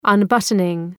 Leave a reply unbuttoning Dëgjoni shqiptimin https